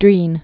(drēn)